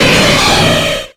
Cri de Cizayox dans Pokémon X et Y.